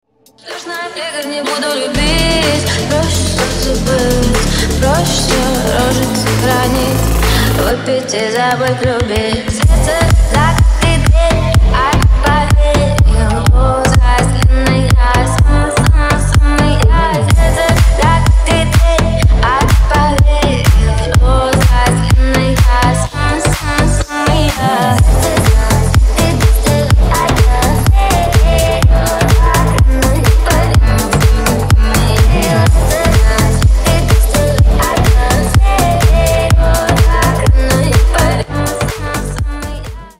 Ремикс
клубные